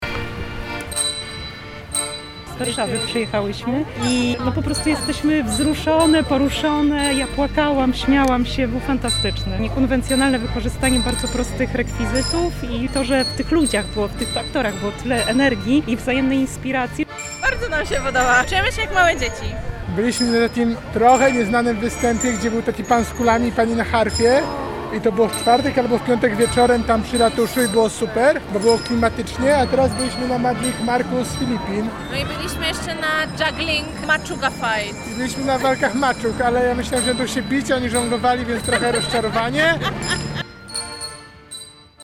Ma miejscu byli nasi reporterzy, którzy rozmawiali z publicznością.
Sztukmistrze-relacja.mp3